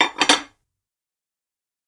Household Items Sound